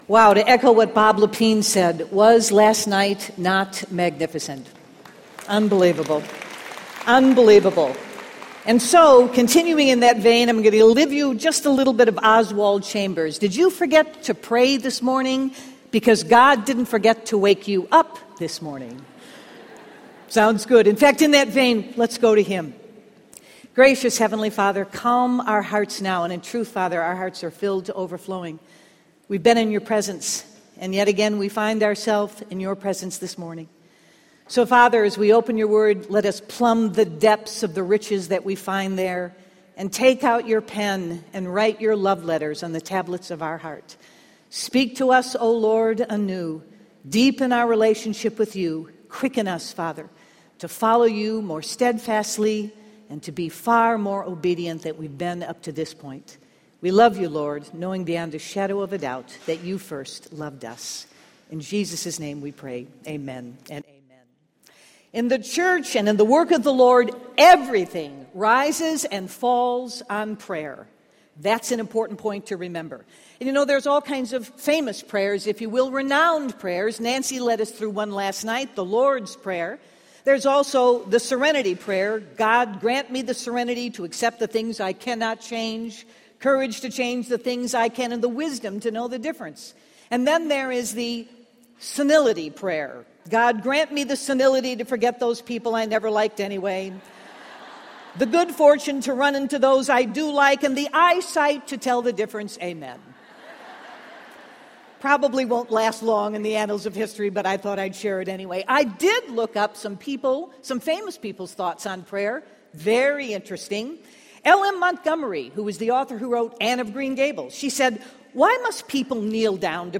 The Pattern of Prayer | True Woman '16 | Events | Revive Our Hearts